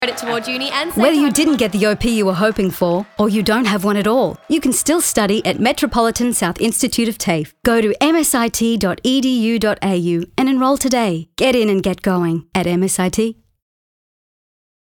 Female
Radio Commercials
Upbeat Radio Commercial